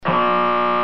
Buzzer (good)